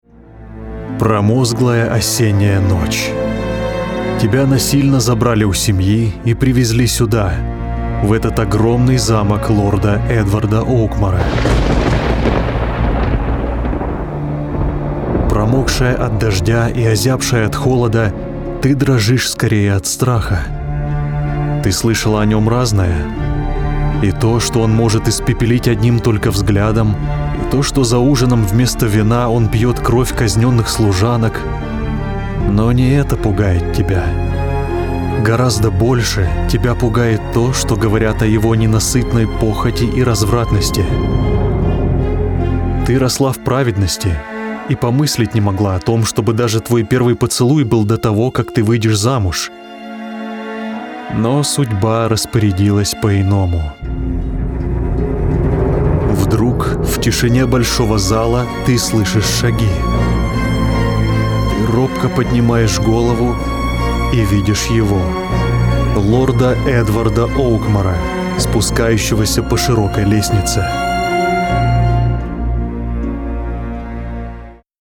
Une prestation rapide et de qualité studio pour des résultats exceptionnels.
Livres audio
Rode NT-1; Audience ID14
Baryton